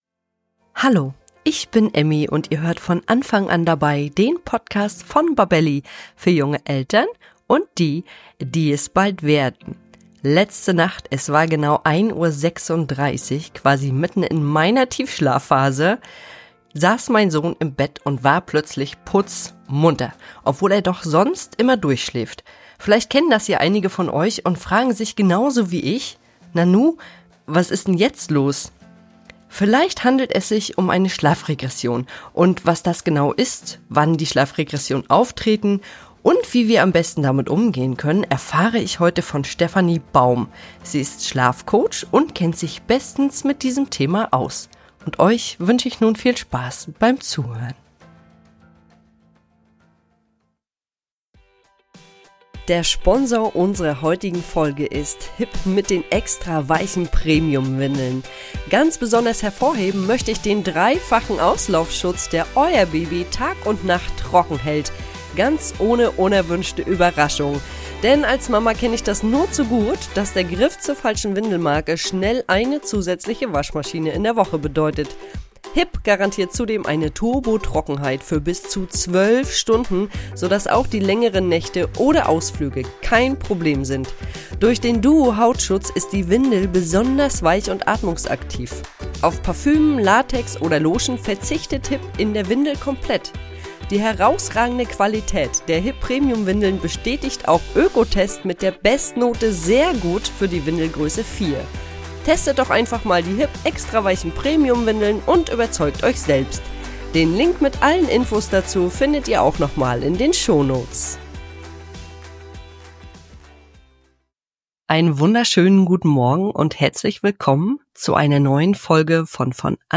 Wir erklären, warum das passiert, welche Altersphasen besonders betroffen sind und wie du dein Kind (und dich selbst!) am besten unterstützt. Schlafexpertin